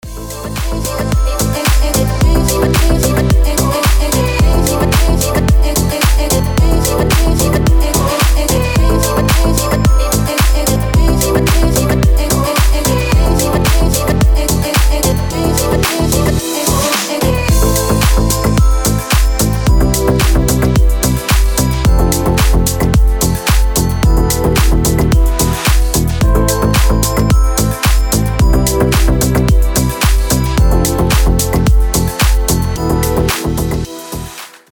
• Качество: 320, Stereo
мелодичные
Стиль: deep house, nu disco